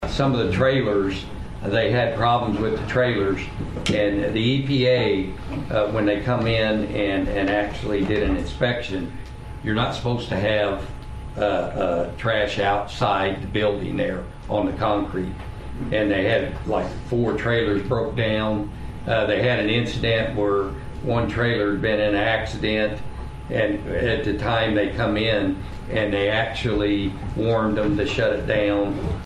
St. Francois Co., Mo. (KFMO) - Controversy surrounding intermittent closures at the St. Francois County Transfer Station was addressed during Tuesday’s St. Francois County Commission meeting.